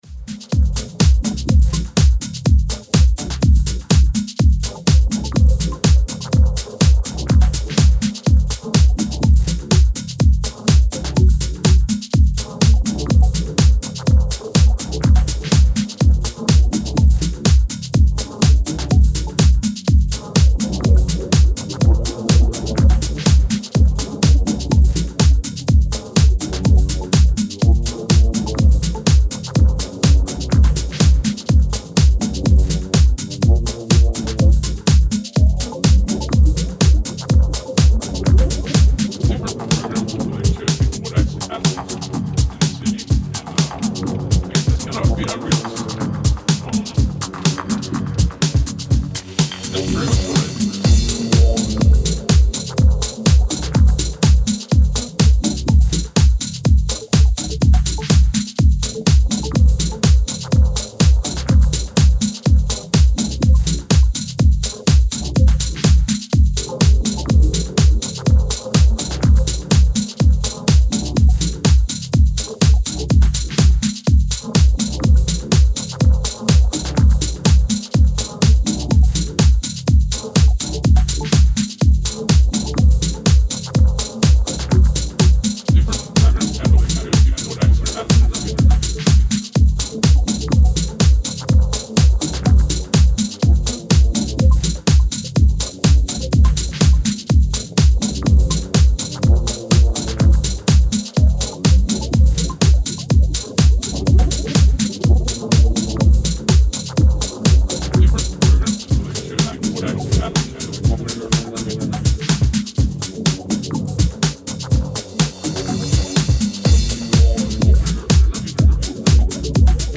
Style: Techno / Minimal